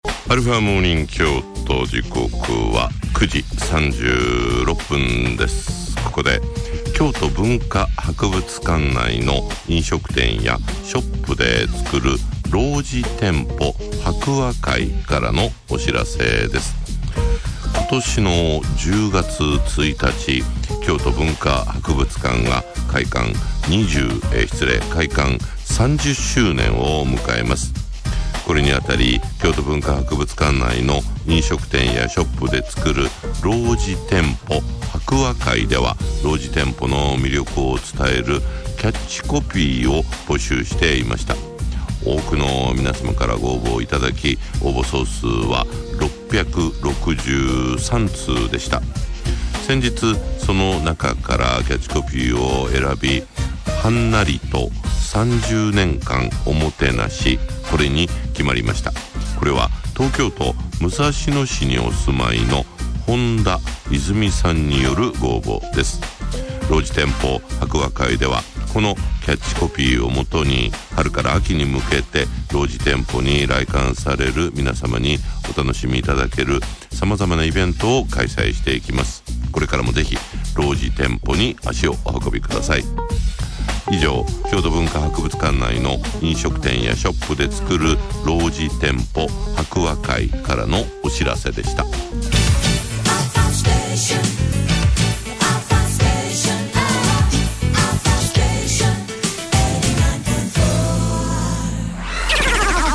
cm